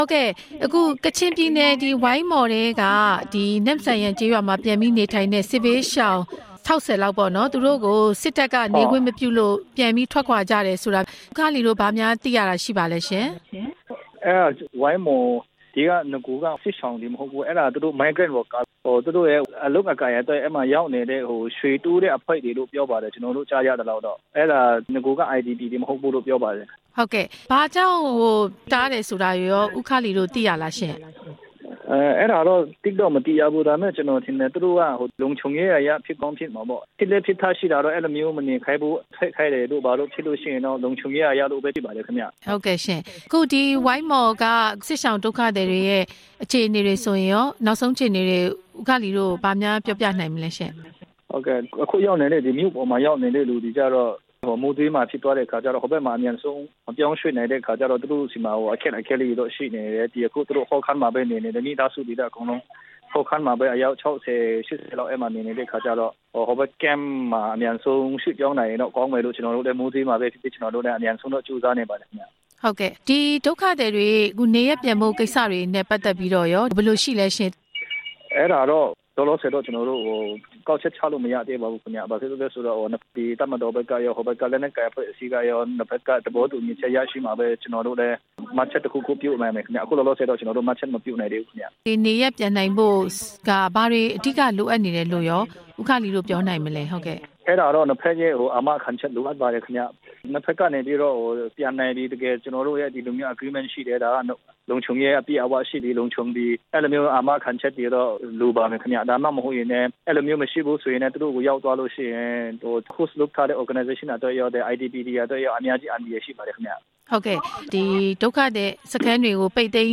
ဝိုင်းမော်ဒုက္ခသည်တွေရဲ့အခြေအနေ ဆက်သွယ်မေးမြန်းချက်